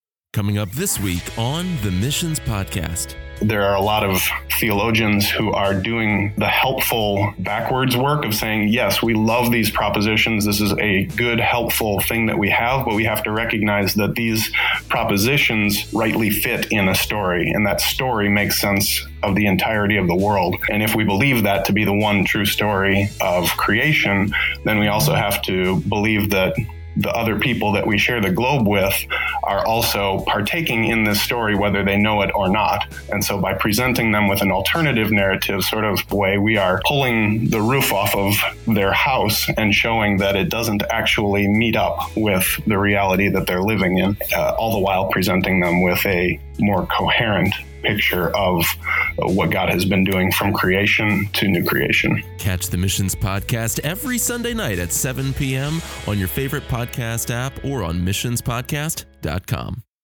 insightful conversation